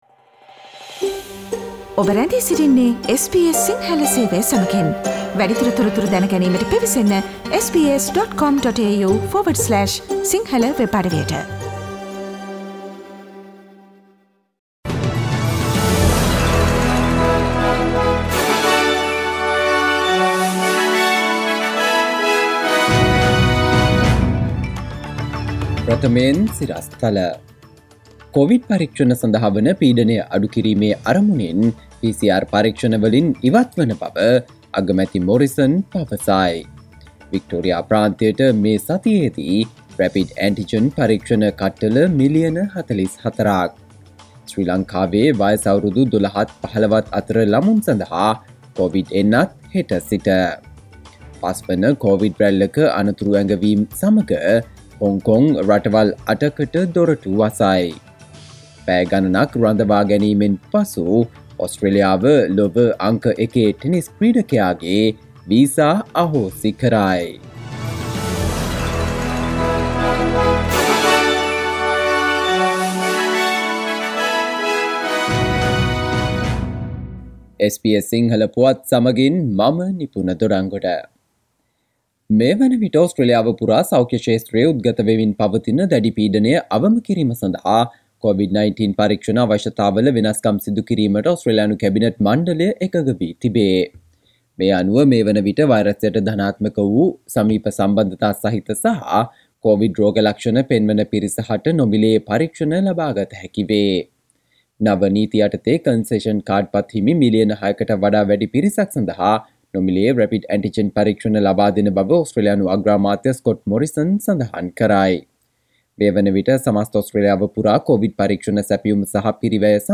සවන්දෙන්න 2022 ජනවාරි 06 වන බ්‍රහස්පතින්දා SBS සිංහල ගුවන්විදුලියේ ප්‍රවෘත්ති ප්‍රකාශයට...
SBS සිංහල සේවයේ දෛනික ප්‍රවෘත්ති ප්‍රකාශය